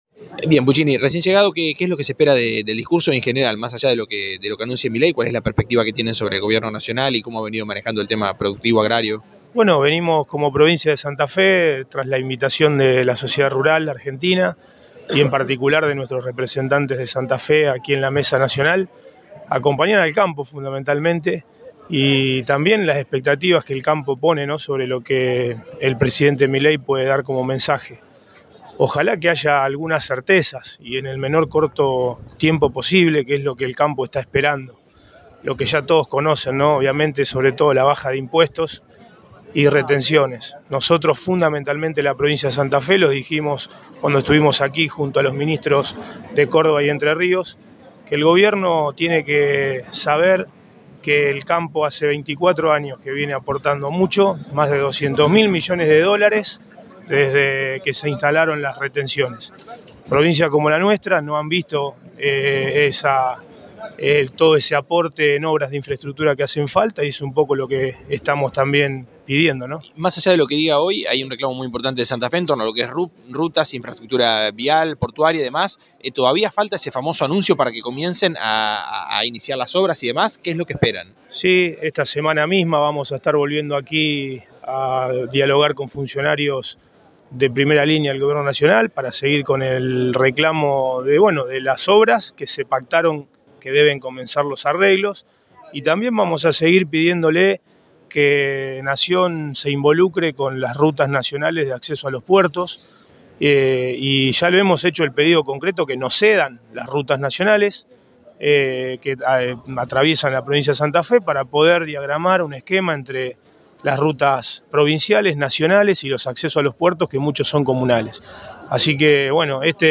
El ministro de Desarrollo Productivo de Santa Fe visitó la muestra y dialogó con Cadena 3 Rosario. Se refirió también a la importancia de las obras viales.